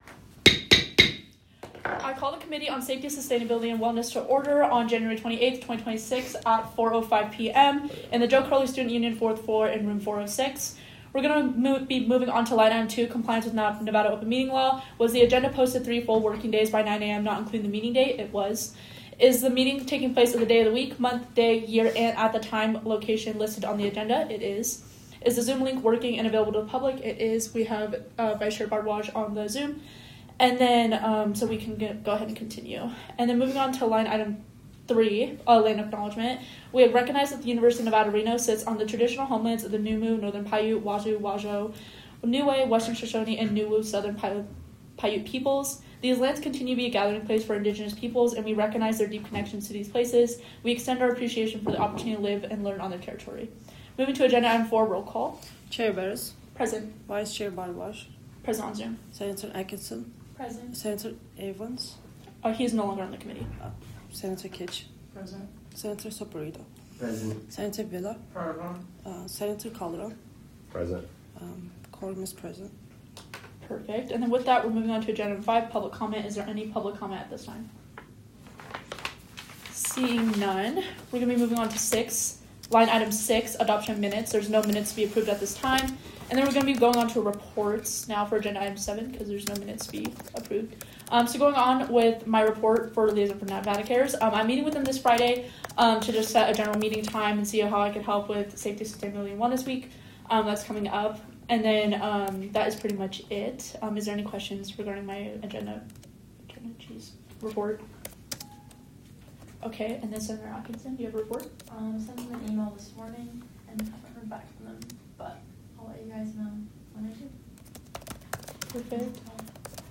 Meeting Type : Safety, Sustainability, and Wellness Committee
Location : JCSU 406